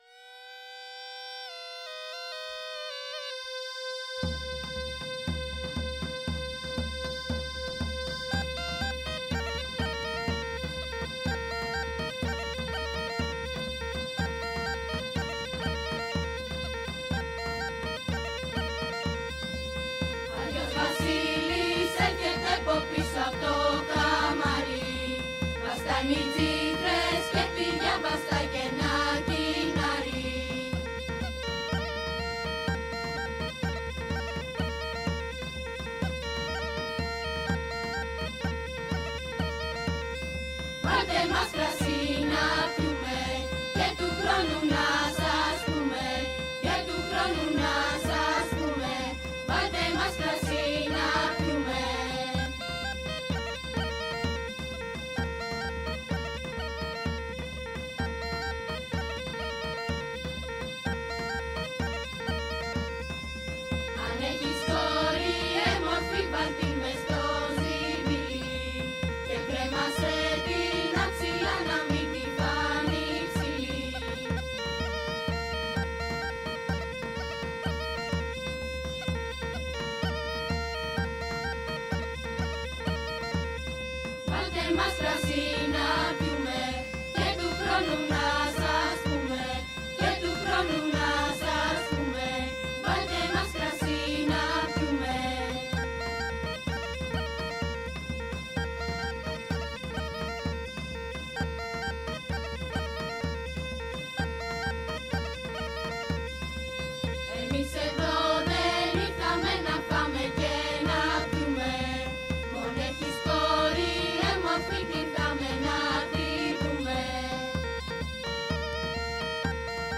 Οι ακροατές της ΕΡΑ ΣΠΟΡ θα ταξιδέψουν σε διάφορες περιοχές της Ελλάδας αλλά και των λεγόμενων αλησμόνητων πατρίδων, όπως για παράδειγμα στην Καππαδοκία, και θα ακούσουν συναρπαστικές προφορικές μαρτυρίες, κάλαντα και παραμύθια.